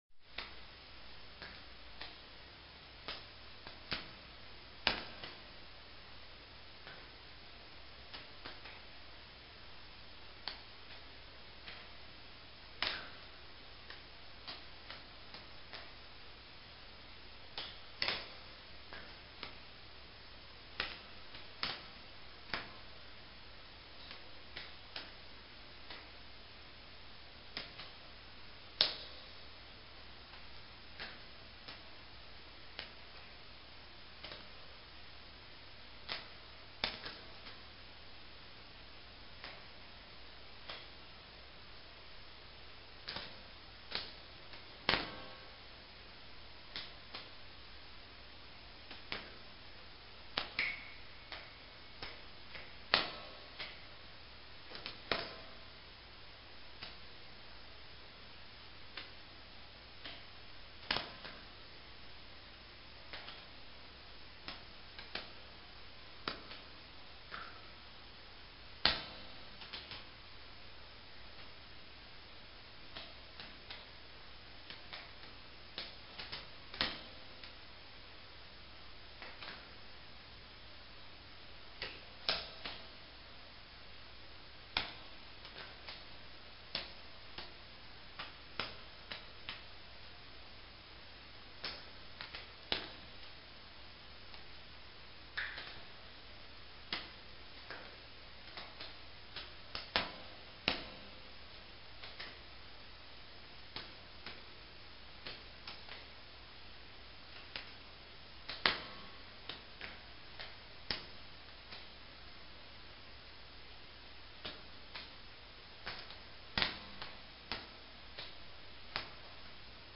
3td_Cave_Drip_01.ogg